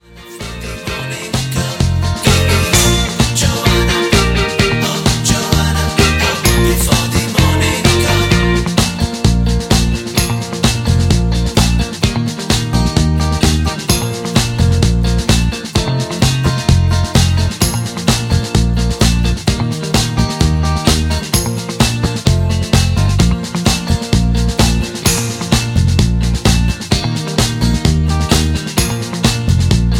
Backing track files: 1980s (763)